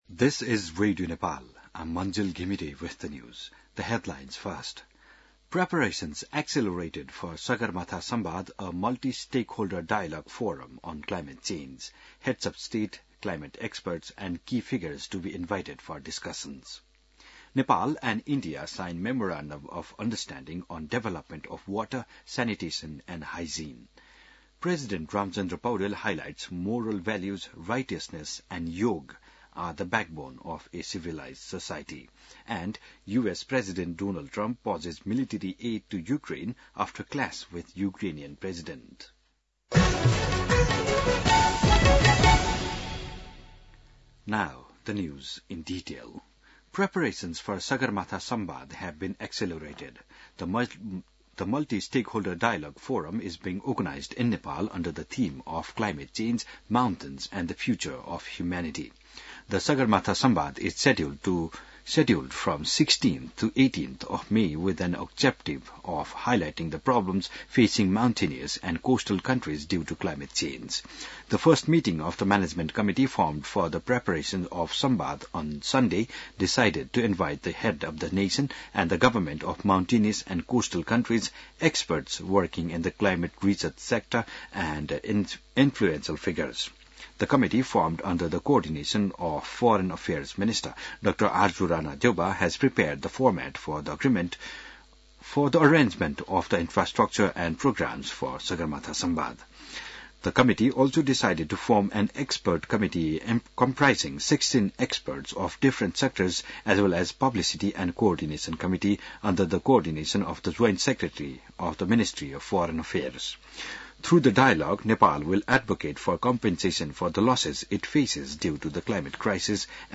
बिहान ८ बजेको अङ्ग्रेजी समाचार : २१ फागुन , २०८१